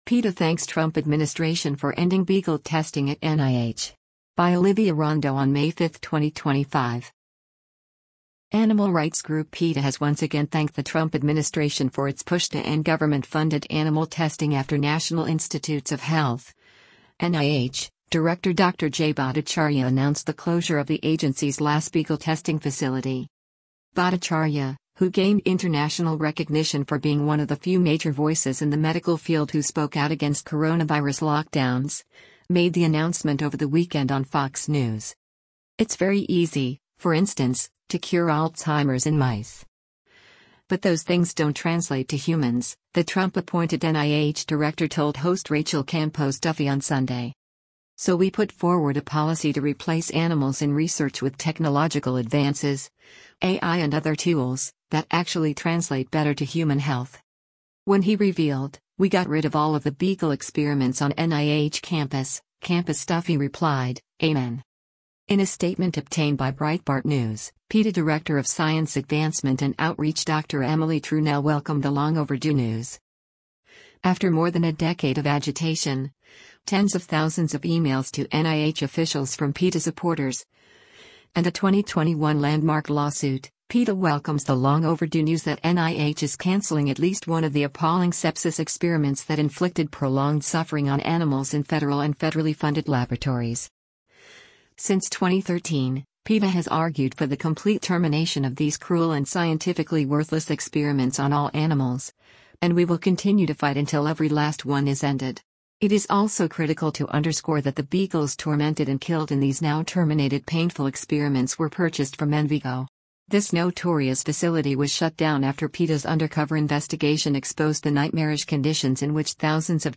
Bhattacharya, who gained international recognition for being one of the few major voices in the medical field who spoke out against coronavirus lockdowns, made the announcement over the weekend on Fox News:
“It’s very easy, for instance, to cure Alzheimer’s in mice. But those things don’t translate to humans,” the Trump-appointed NIH director told host Rachel Campos-Duffy on Sunday.